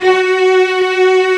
Index of /90_sSampleCDs/Roland LCDP13 String Sections/STR_Combos 2/CMB_Str.Orch Oct